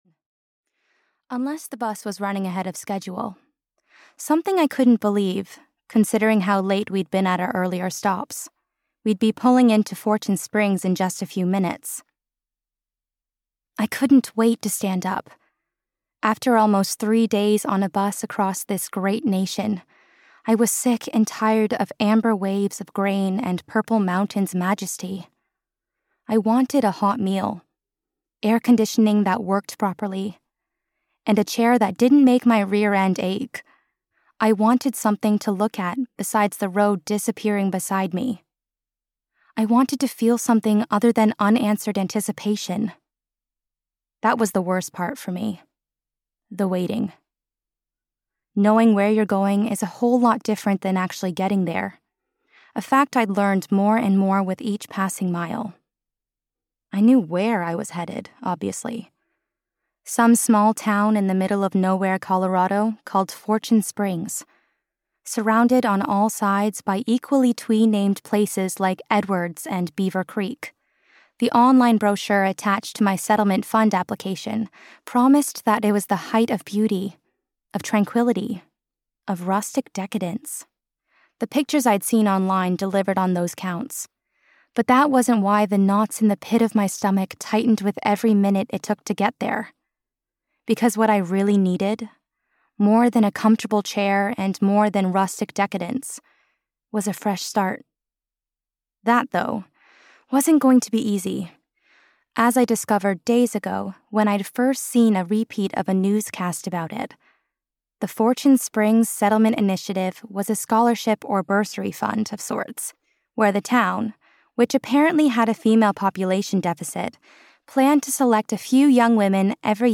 Small Town Secrets (EN) audiokniha
Ukázka z knihy